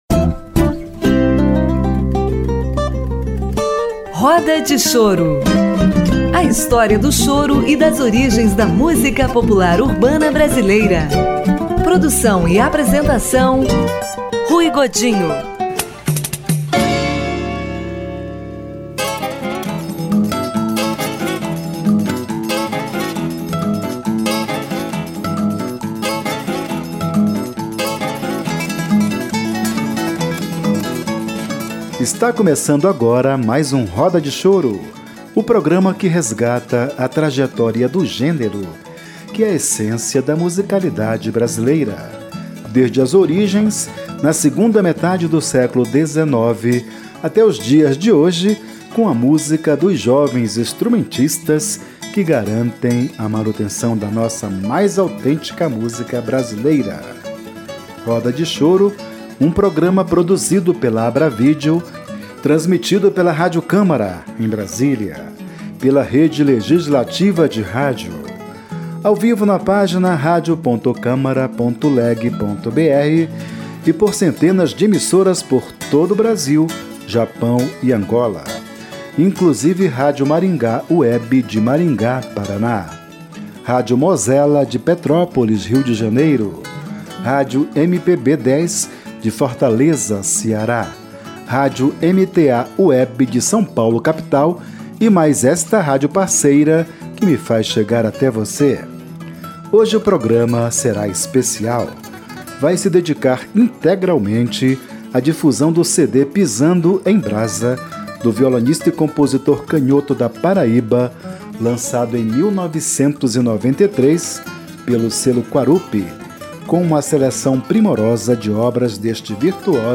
violonista